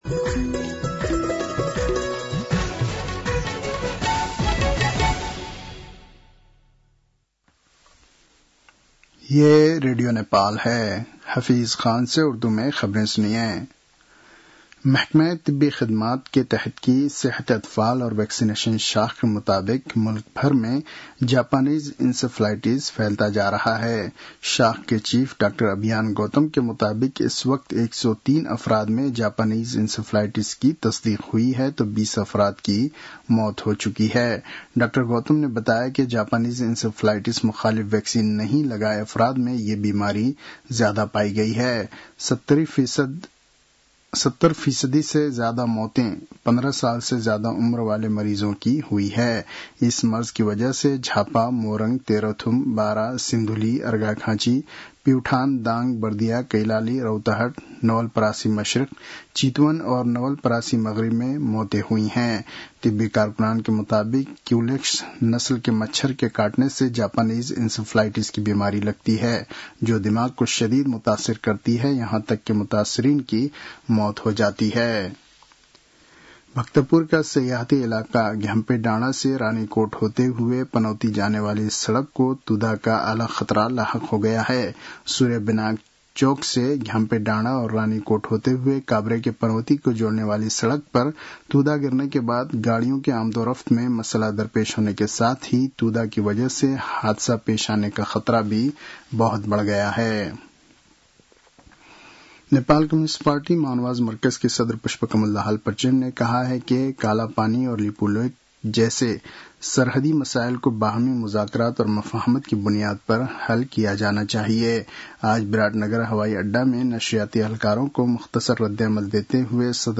उर्दु भाषामा समाचार : १२ भदौ , २०८२